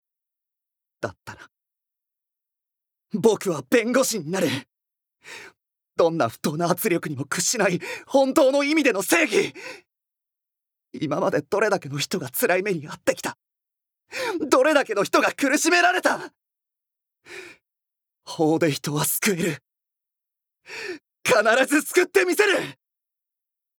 ボイスサンプル
セリフ１